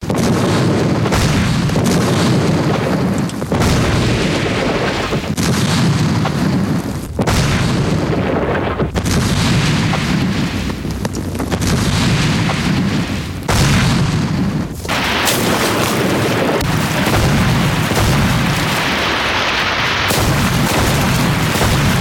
На этой странице представлены аудиозаписи, имитирующие звуки выстрелов.
Грохот битвы